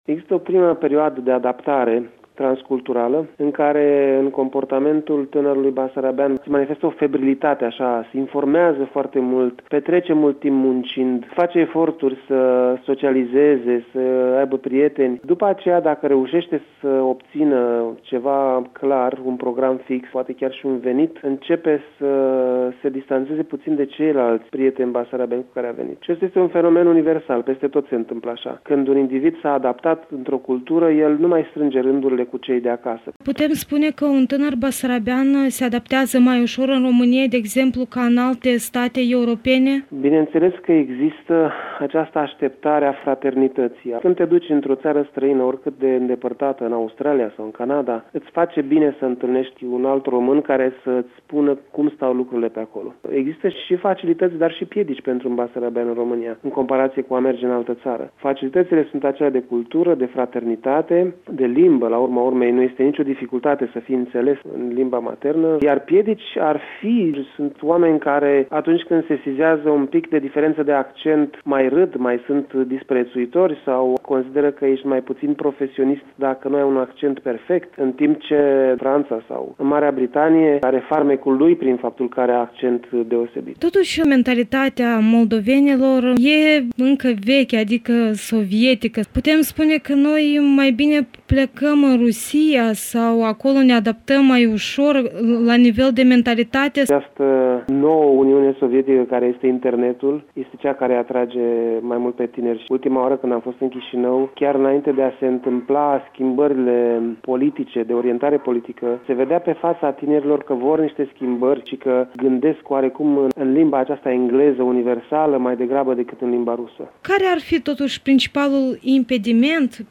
Un interviu cu psihologul